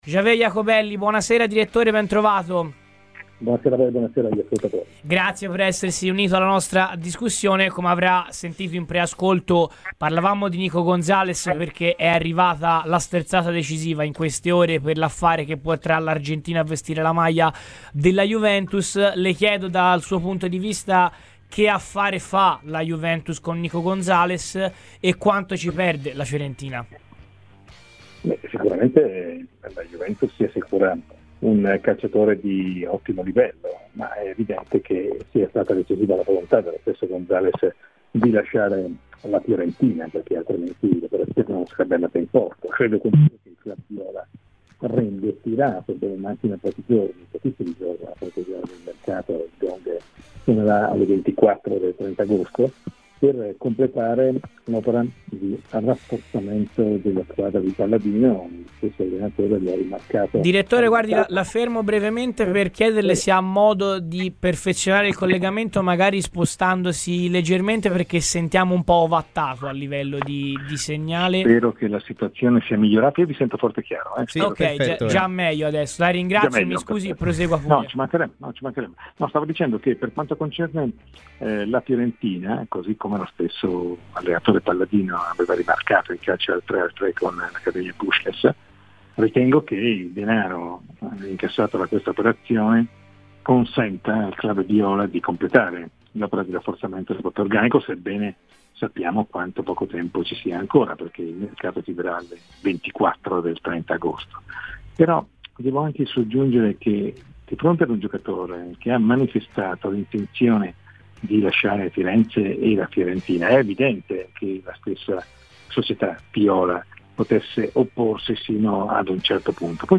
Il giornalista Xavier Jacobelli è intervenuto a Radio FirenzeViola durante "Viola weekend" parlando della cessione di Nico Gonzalez alla Juventus.